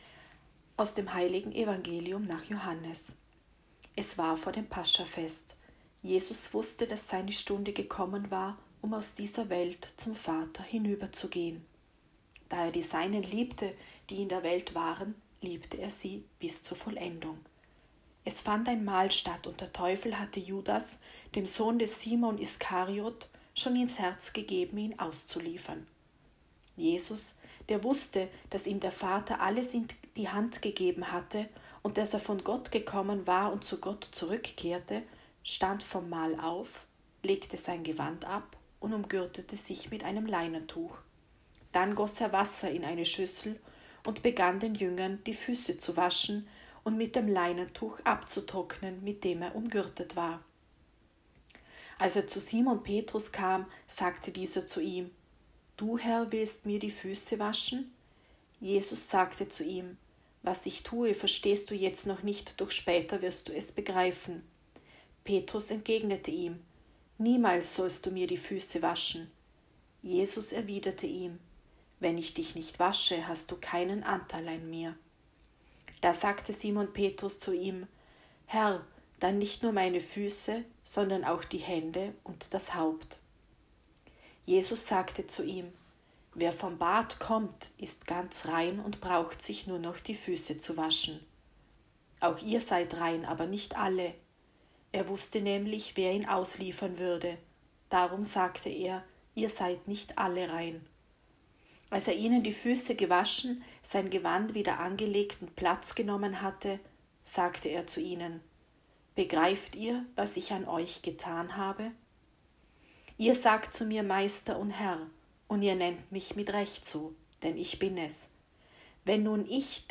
Evangelium-Gründonnerstag-2020.m4a